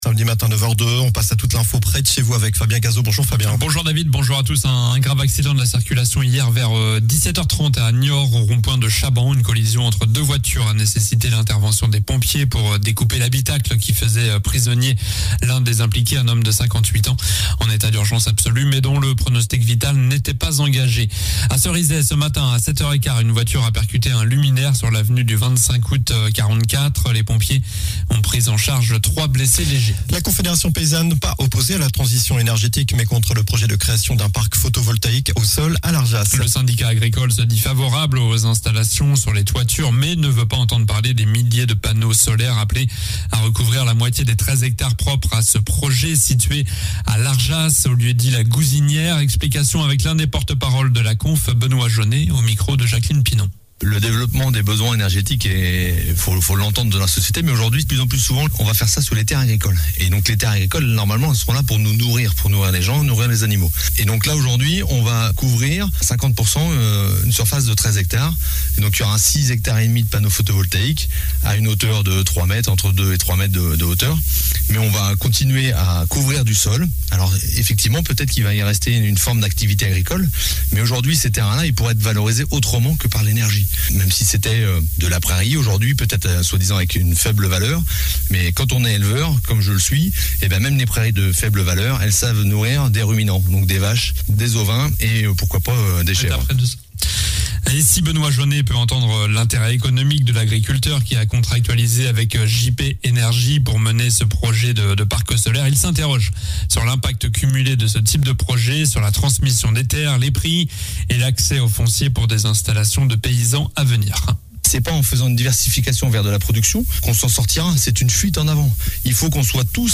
Journal du samedi 16 octobre